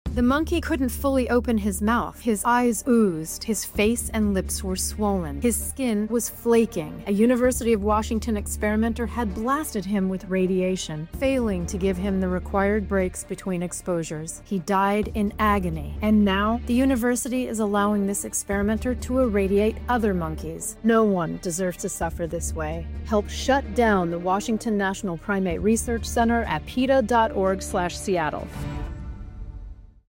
Instructions for Downloading This Radio PSA Audio File
blasted_with_radiation_radio_ad_peta_us.mp3